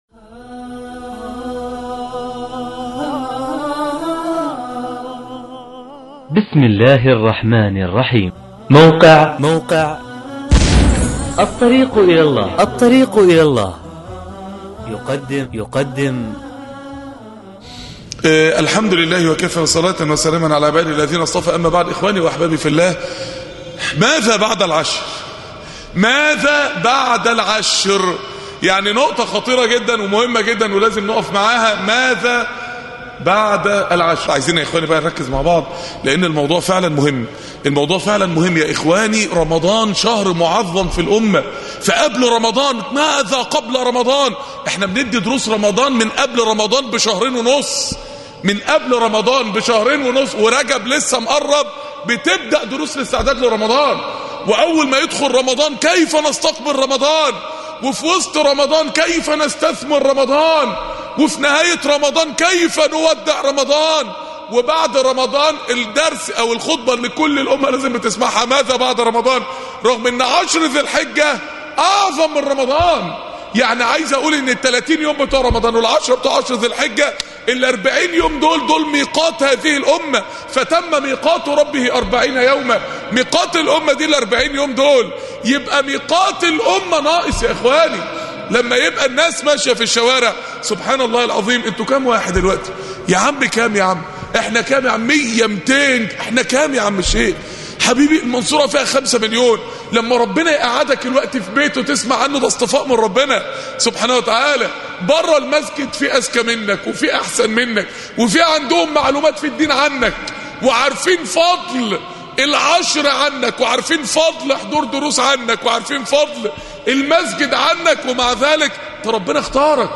الجمعية الشرعية بالمنصورة